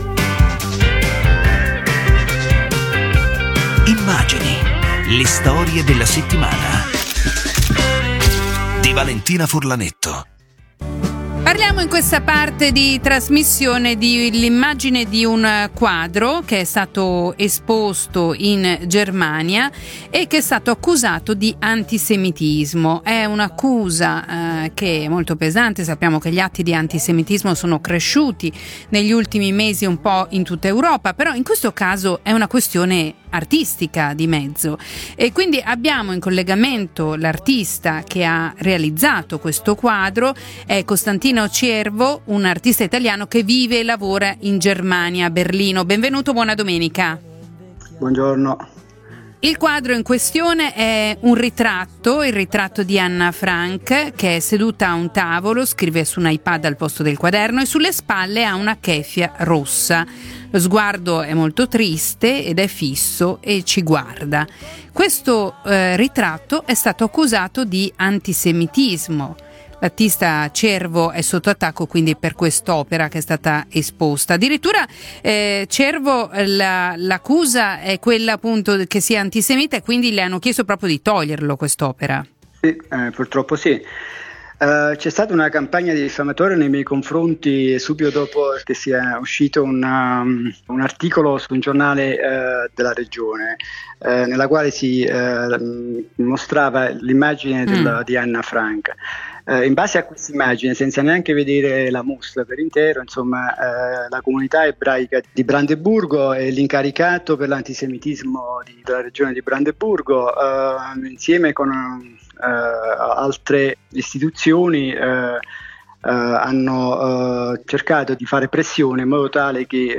intervista al punto 17:16 min. trasmissione 21/12/25